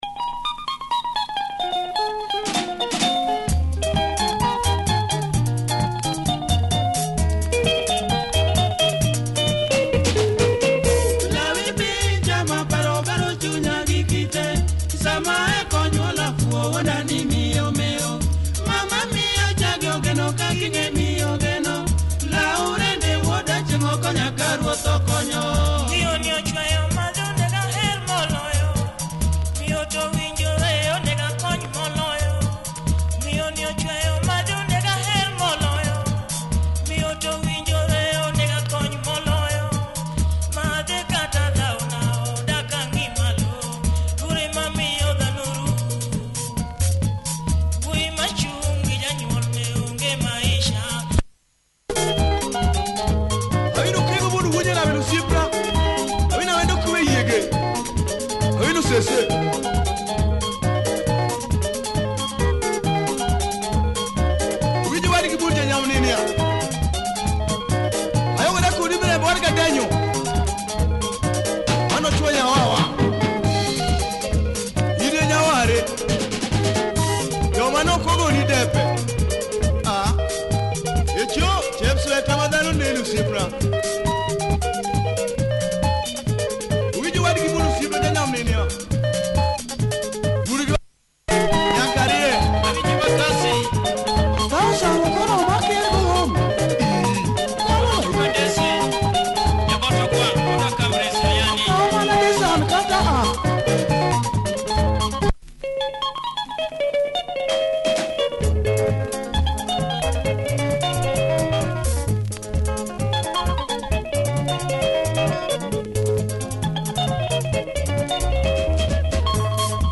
Pumping luo benga